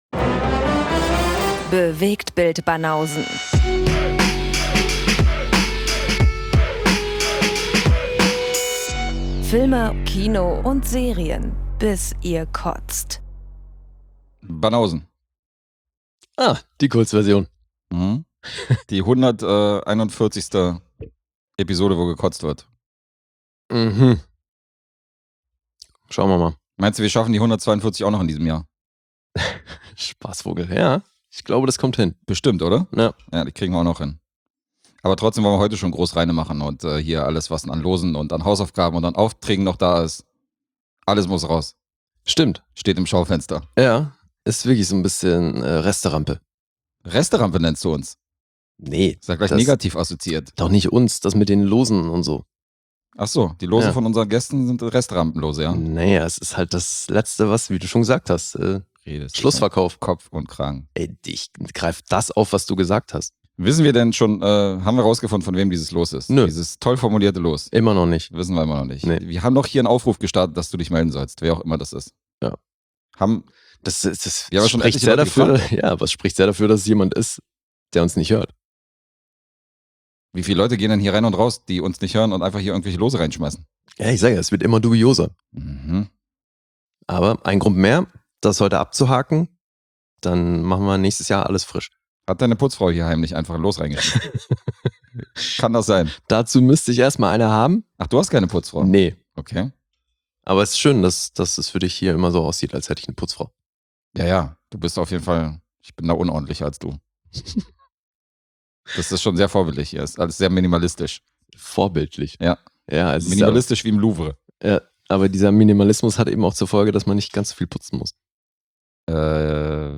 Zwei Dudes - manchmal mit Gästen - quatschen über Gesehenes aus Kino, Homekino und Streaming-Plattformen und punkten zudem mit gefährlichem Halbwissen.